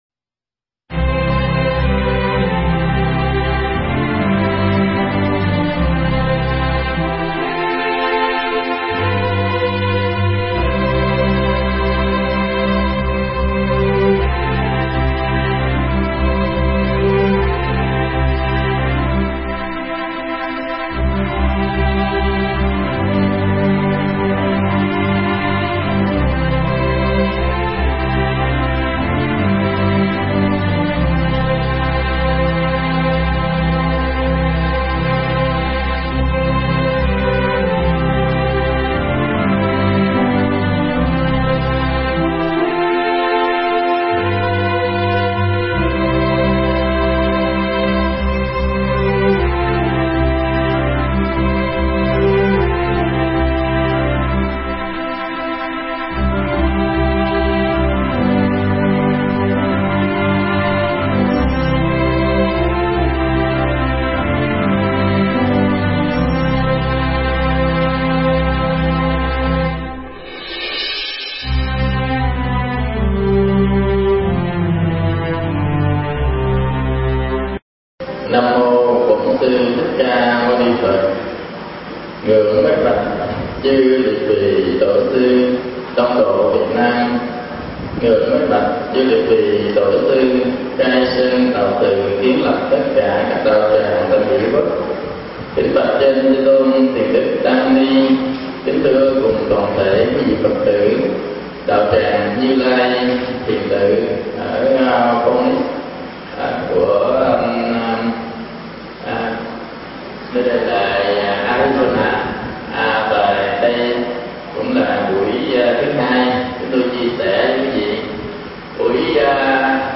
Mp3 Thuyết Pháp Mượn tướng sửa tâm
thuyết giảng tại Như Lai Thiền Tự, Califorcia, Mỹ Quốc